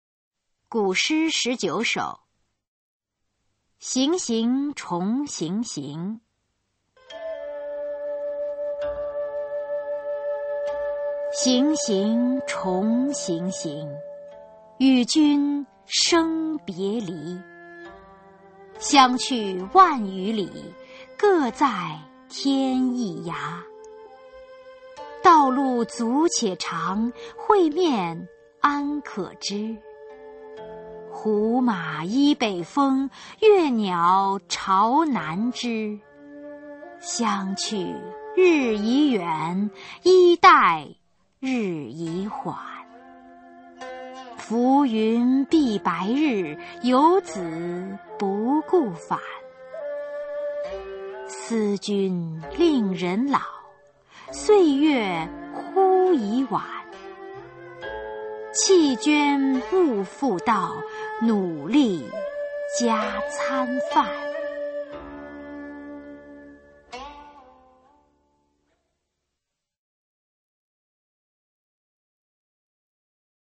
[先秦诗词诵读]古诗十九首-行行重行行 朗诵